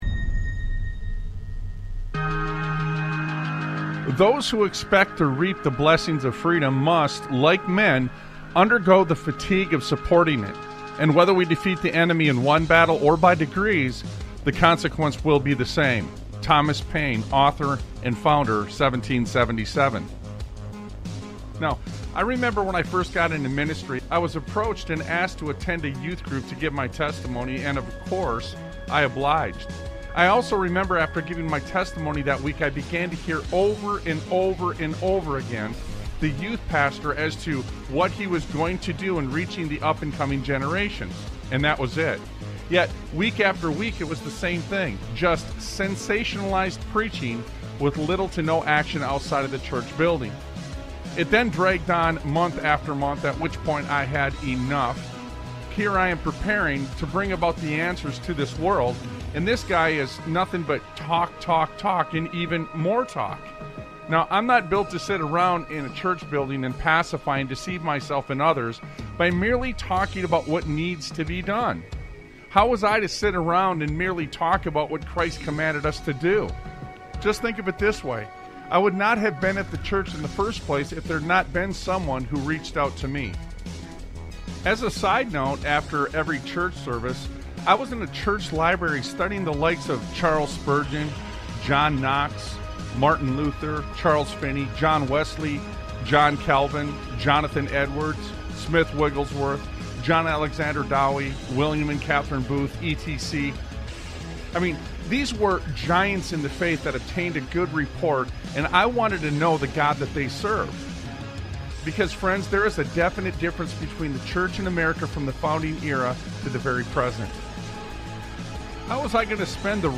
Talk Show Episode, Audio Podcast, Sons of Liberty Radio and The Unsung Heroes on , show guests , about The Unsung Heroes, categorized as Education,History,Military,News,Politics & Government,Religion,Christianity,Society and Culture,Theory & Conspiracy